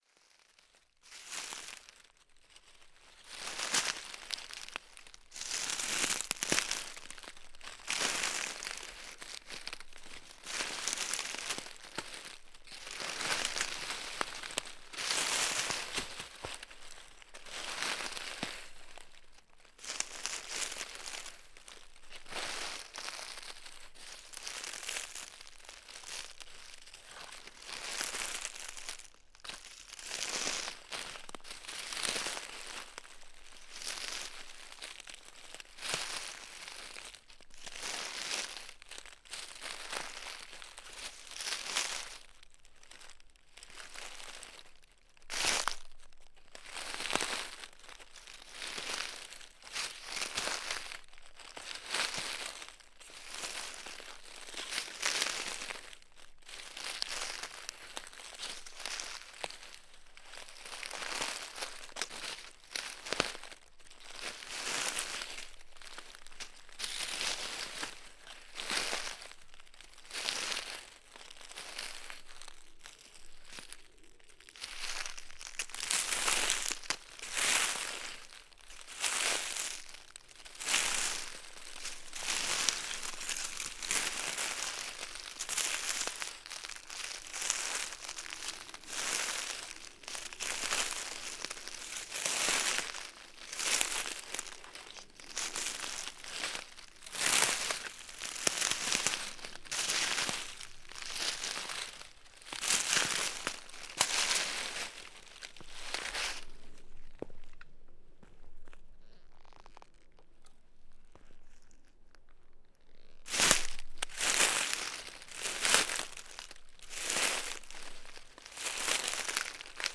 NODAR.00516 – Côta: Caminhada em bosque junto à Avenida das Abelhinhas em Vila Dum Santo